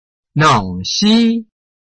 臺灣客語拼音學習網-客語聽讀拼-饒平腔-鼻尾韻
拼音查詢：【饒平腔】nong ~請點選不同聲調拼音聽聽看!(例字漢字部分屬參考性質)